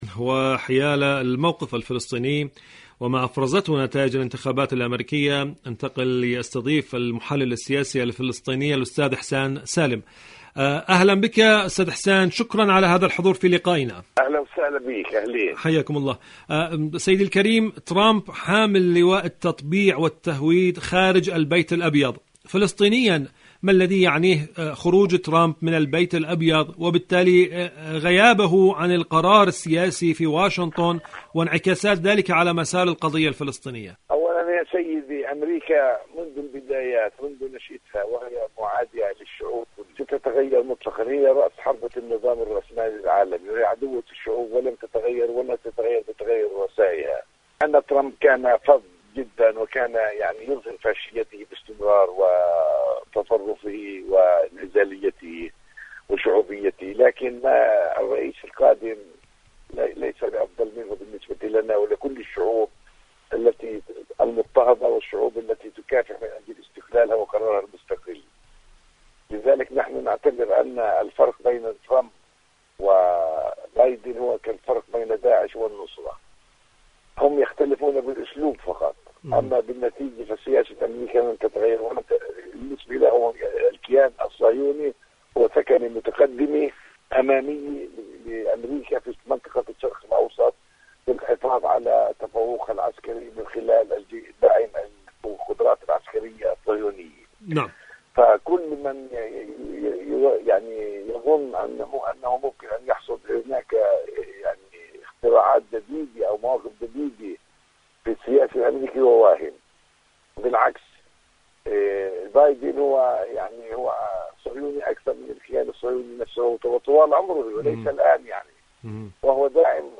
إذاعة طهران-فلسطين اليوم: مقابلة إذاعية